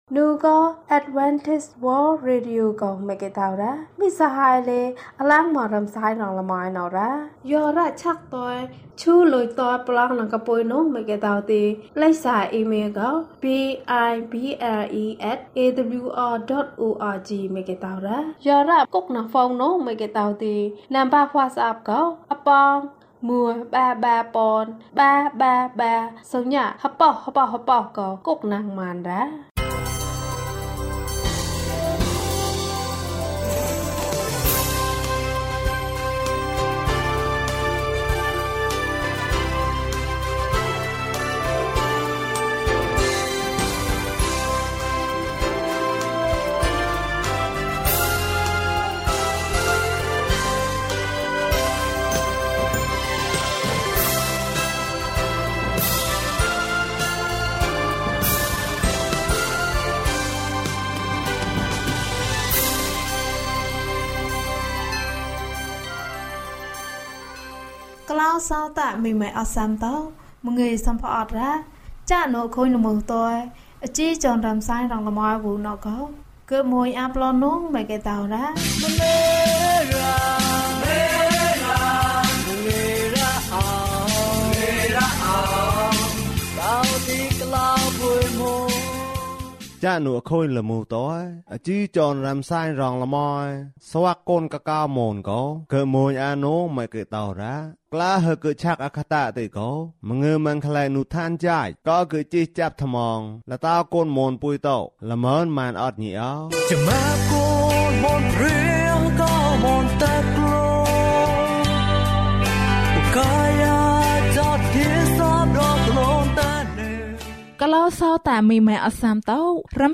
အရာအားလုံးအတွက်။ ကျန်းမာခြင်းအကြောင်းအရာ။ ဓမ္မသီချင်း။ တရားဒေသနာ။